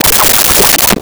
Swirl 02
Swirl 02.wav